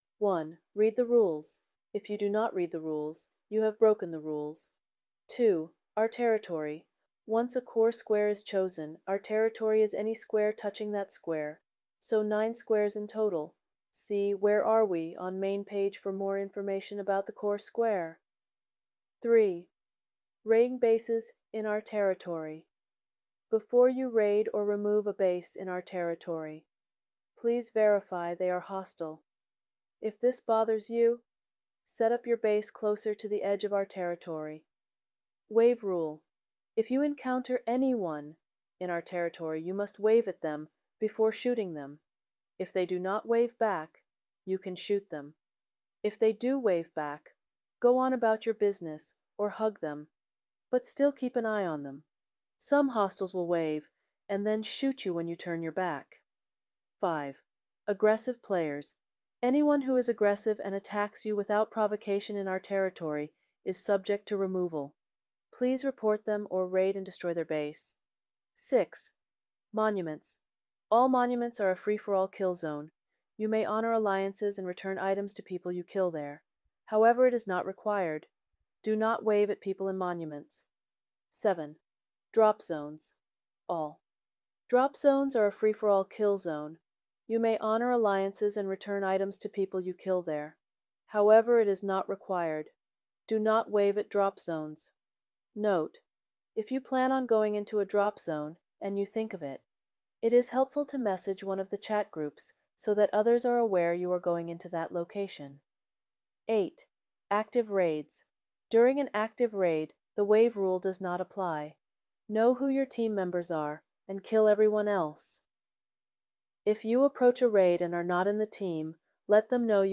Audio Version of Text